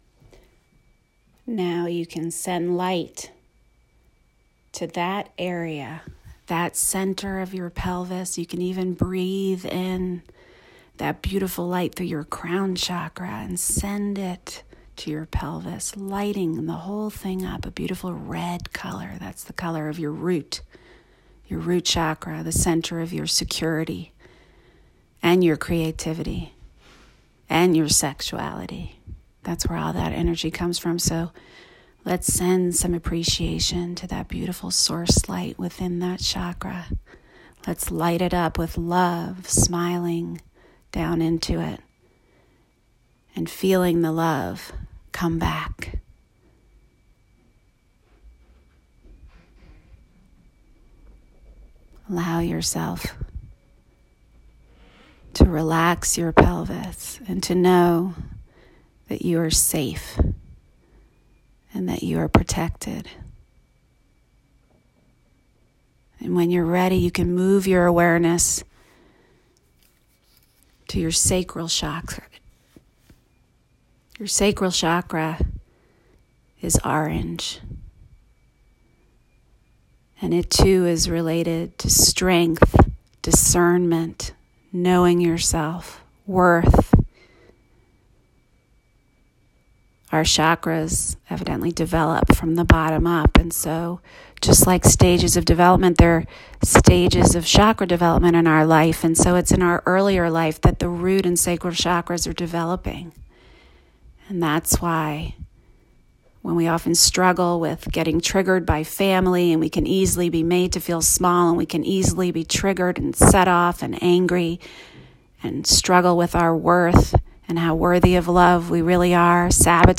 Fortification-Meditation.m4a